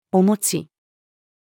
お餅-mochi-female.mp3